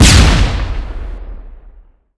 explosion1.wav